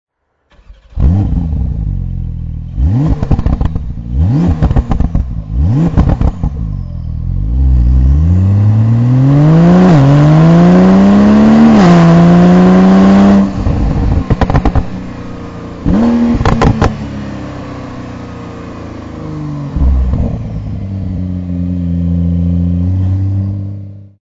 Porsche 911 (992) sportowy układ wydechowy TECHART
Sportowy układ wydechowy Porsche 911 (992) TECHART z regulacją głośności.